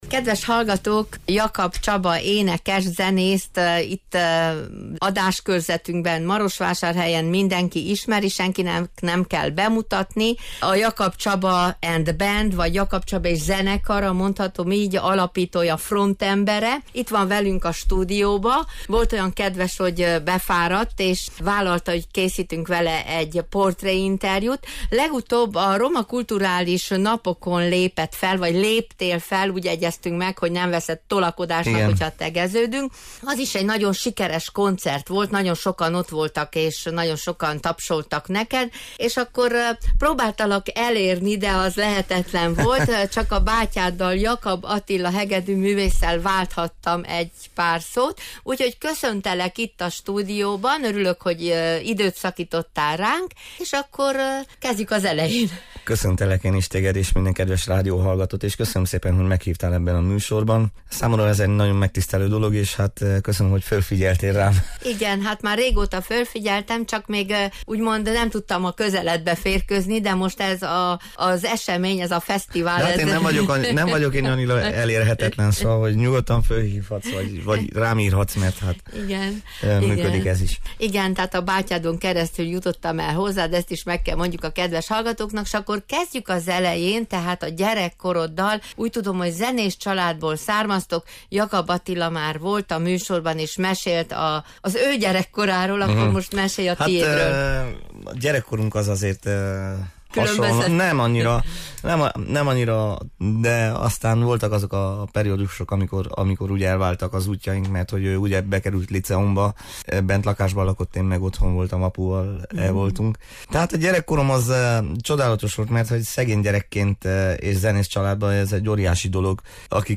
Így hát meghívtuk a stúdióba egy portréinterjúra, amit ő készségesen el is fogadott.